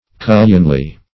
Cullionly \Cul"lion*ly\